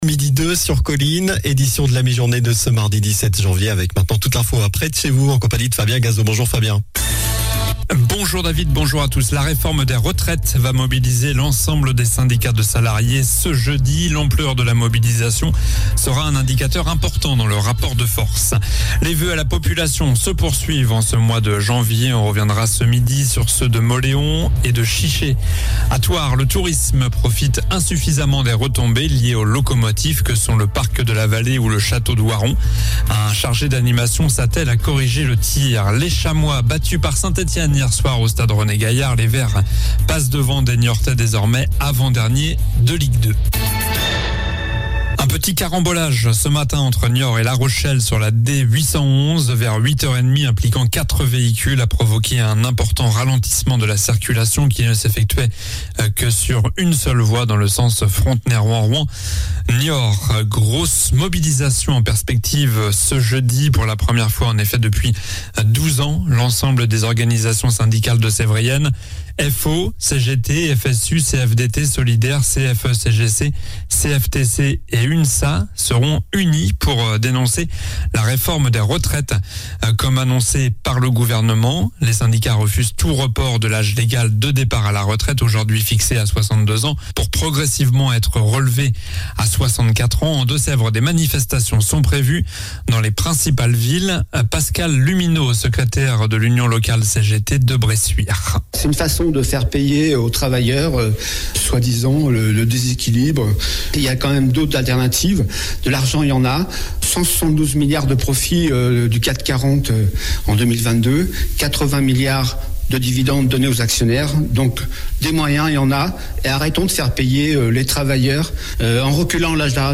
Journal du mardi 17 janvier (midi)